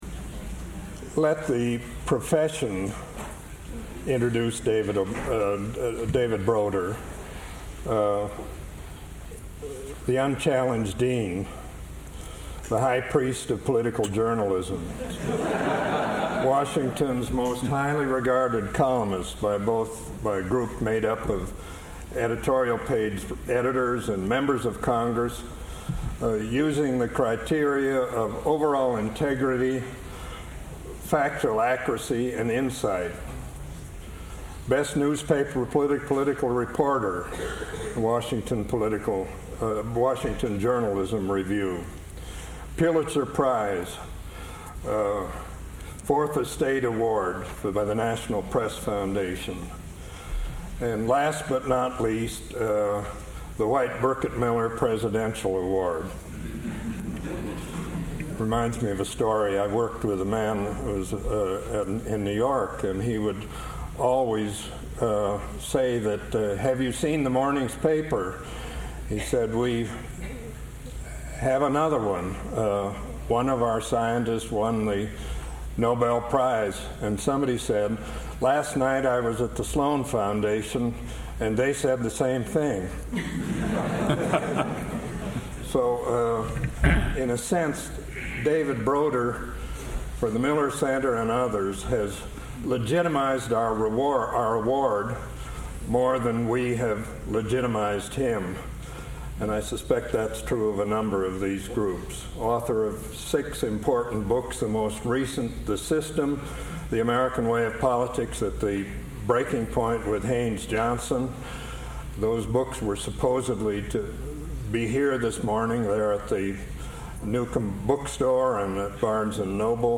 David Broder, a Pulitzer Prize winning author, gives a lecture on the 1996 election. He first begins his discussion with commentary on the low voter turnout, a phenomenon that he attributes to the growing negative campaigning.